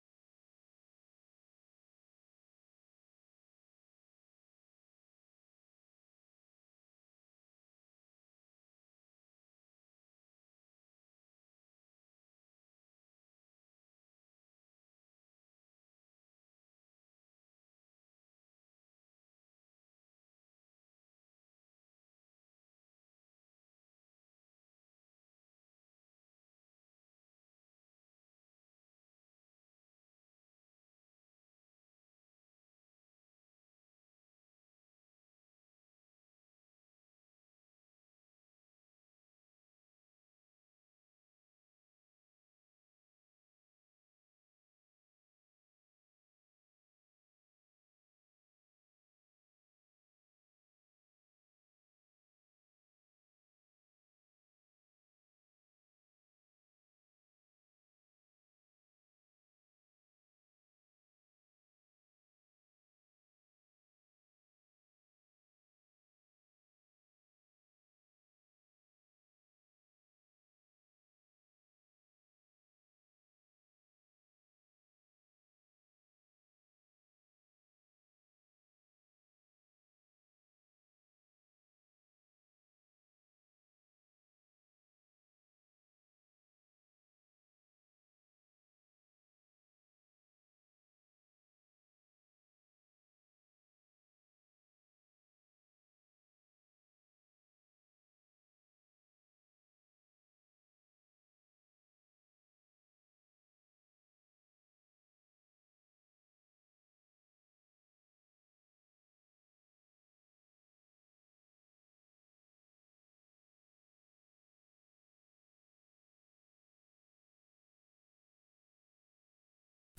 audiodescriptie_pzh_animatie-1_waterveiligheid.mp3